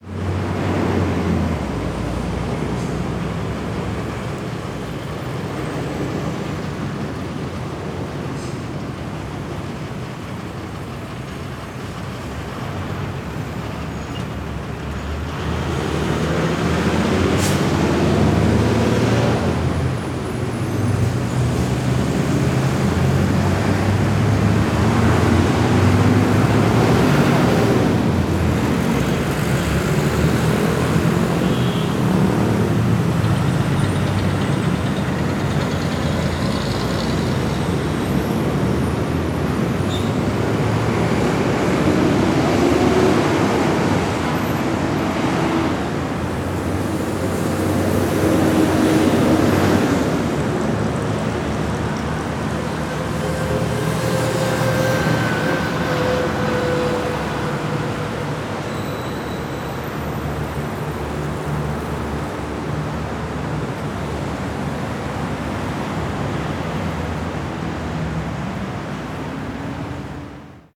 Ambiente de tráfico muy fuerte
tráfico
ruido
Sonidos: Transportes
Sonidos: Ciudad